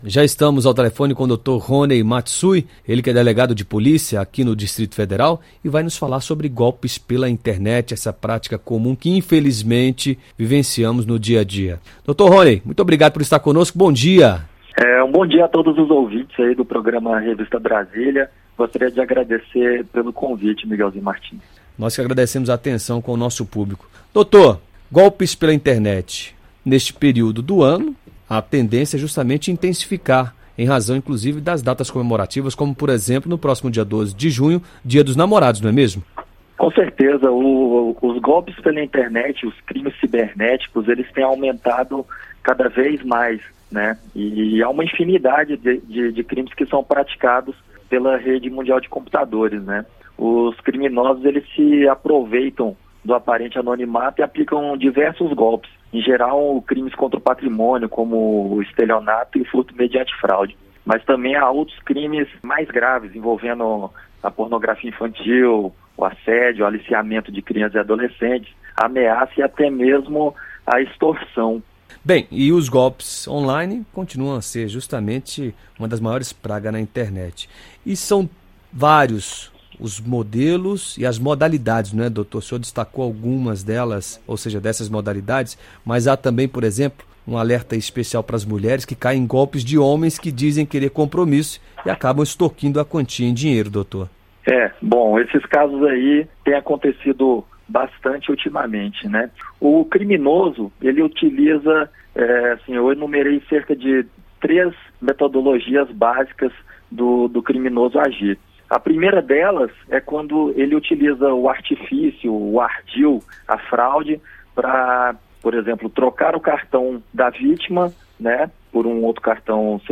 Delegado fala sobre crimes na internet em programa de rádio